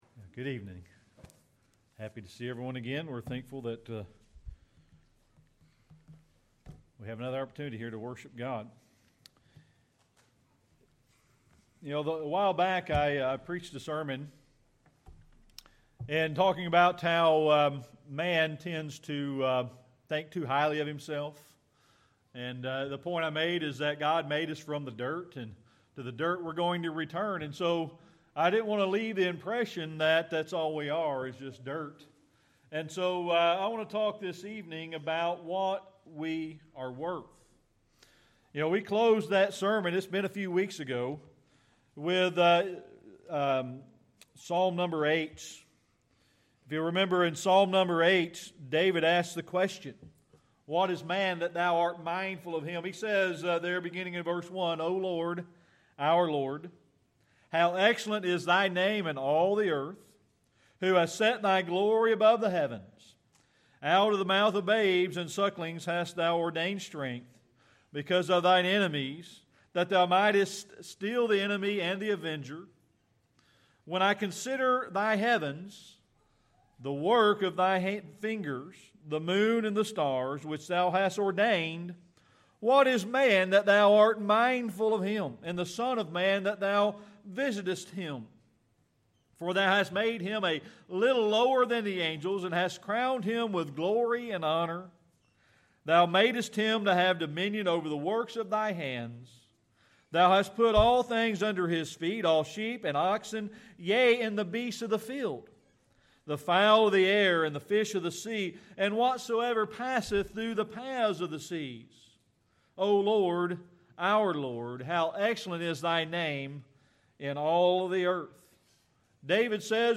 Sermon
Service Type: Sunday Evening Worship